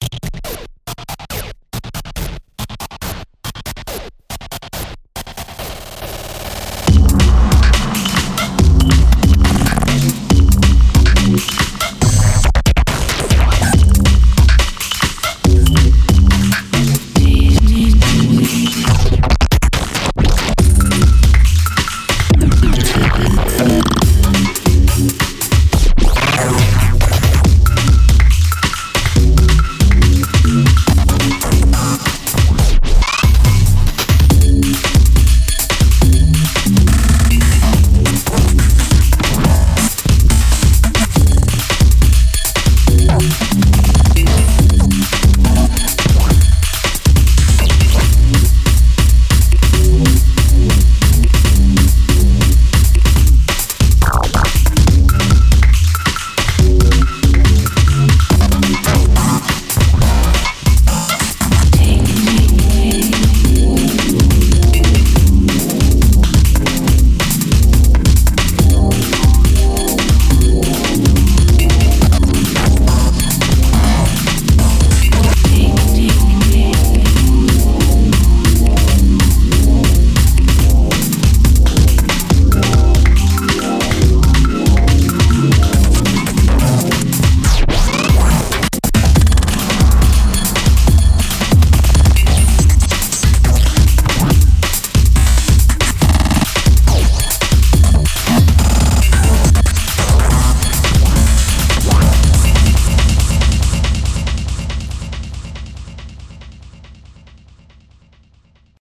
BPM140
Audio QualityPerfect (Low Quality)